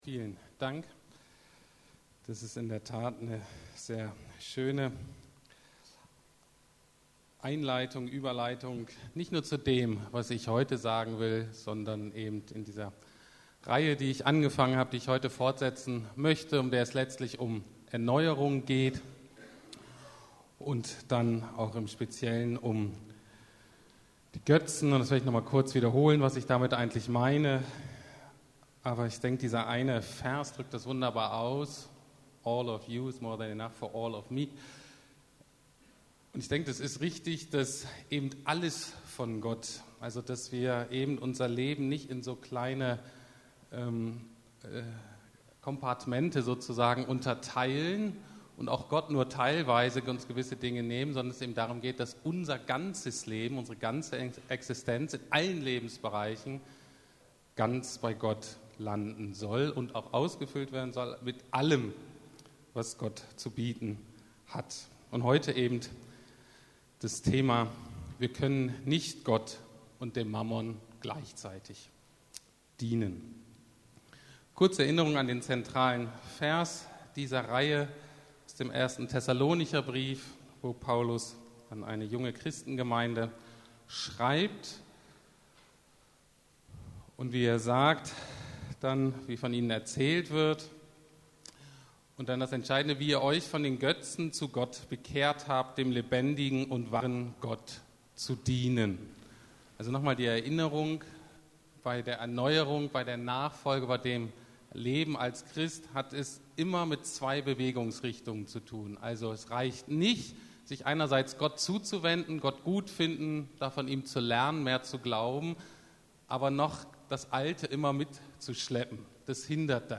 Erneuerung Teil 4: Freiheit vom Mammon ~ Predigten der LUKAS GEMEINDE Podcast